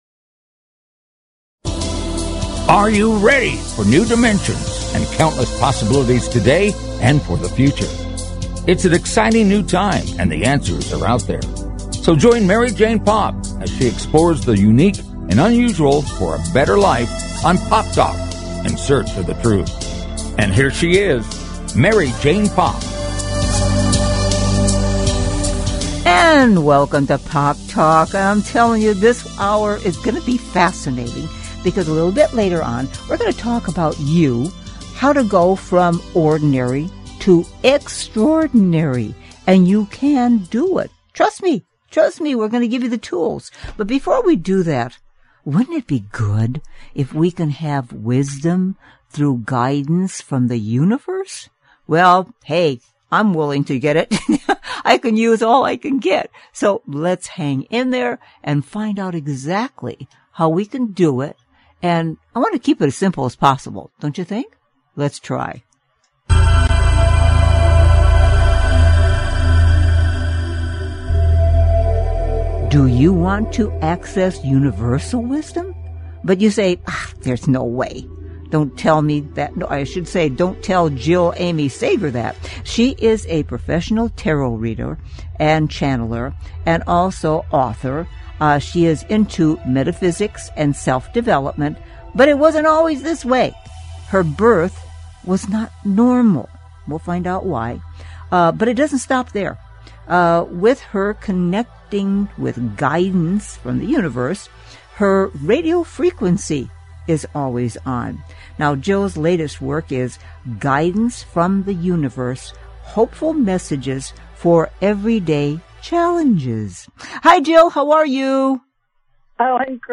The show is high energy, upbeat and entertaining.